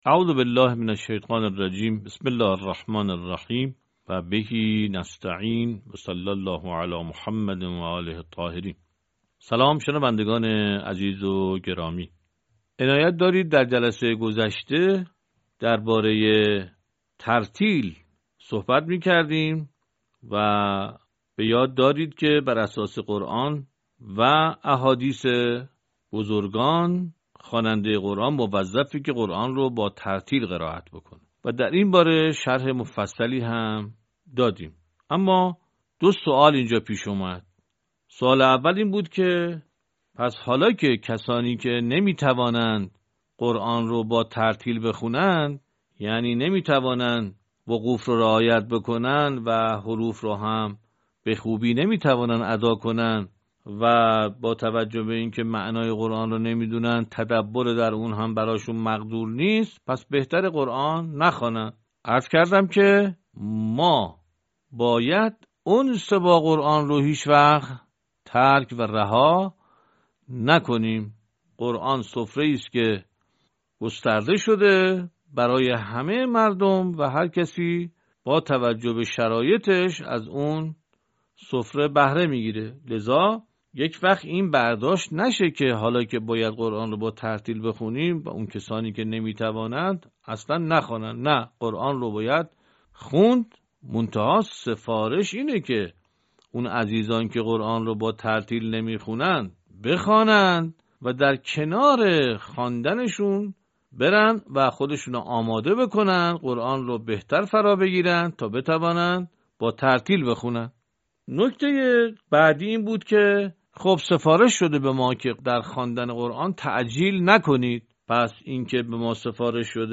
به همین منظور مجموعه آموزشی شنیداری (صوتی) قرآنی را گردآوری و برای علاقه‌مندان بازنشر می‌کند.
آموزش قرآن